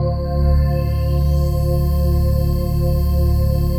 DM PAD2-54.wav